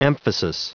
Prononciation du mot emphasis en anglais (fichier audio)
Prononciation du mot : emphasis